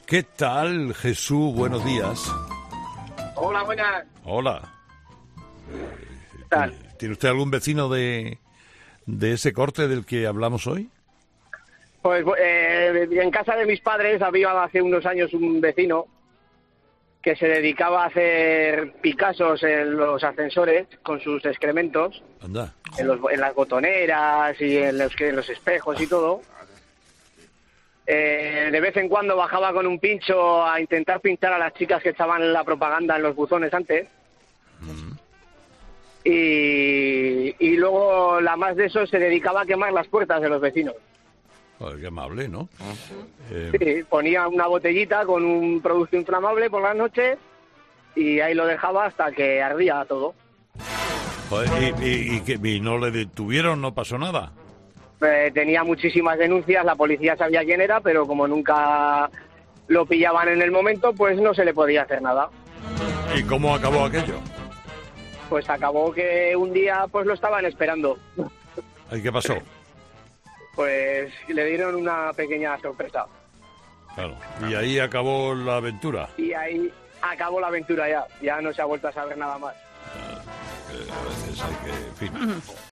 Un oyente de Carlos Herrera cuenta la 'original' forma que tenía un vecino de decorar el ascensor